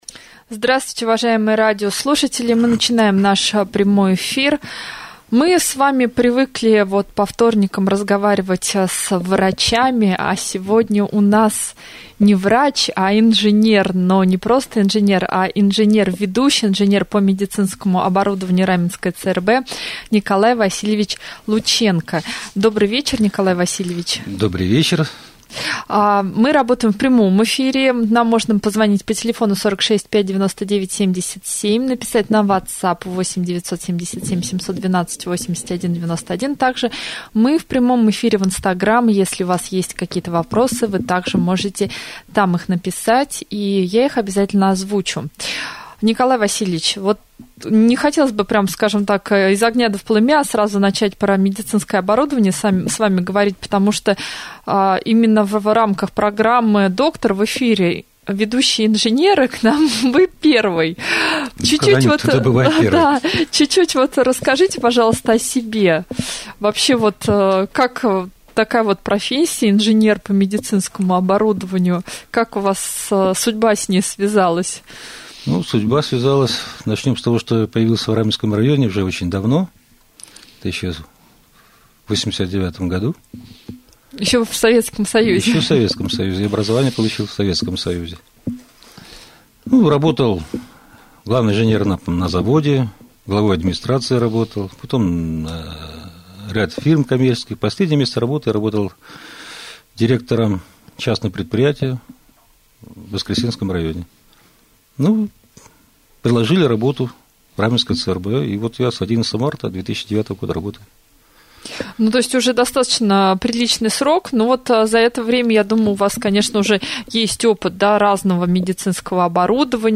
prjamoj-jefir-24-1.mp3